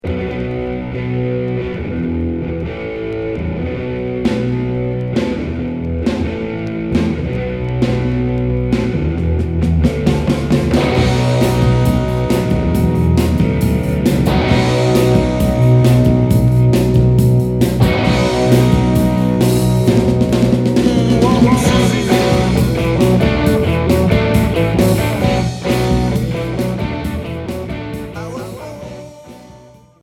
Rock Unique 45t